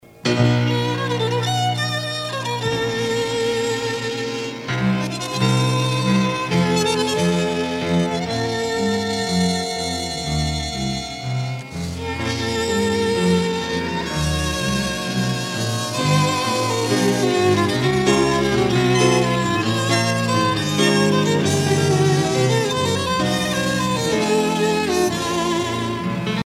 danse : tango (Argentine, Uruguay)